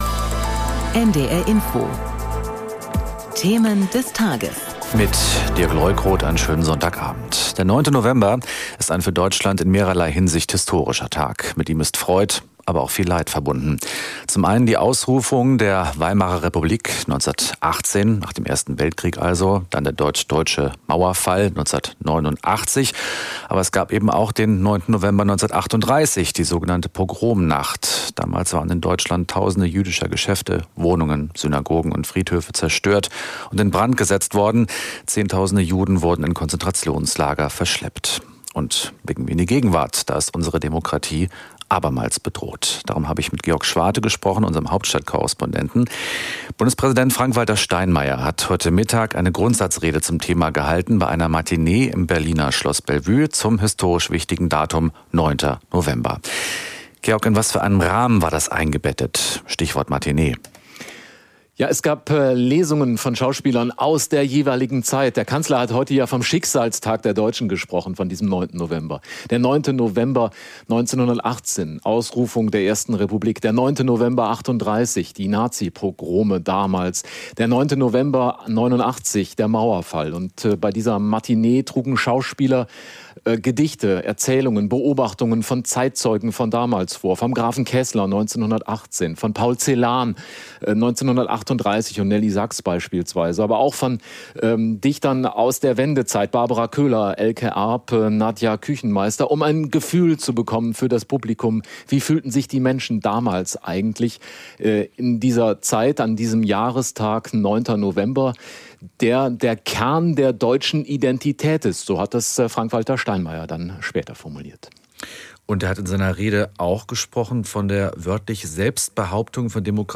Bundespräsident Frank-Walter Steinmeier hat heute am Jahrestag eine Grundsatzrede zum 9. November gehalten, bei einer Matinee im Berliner Schloss Bellevue.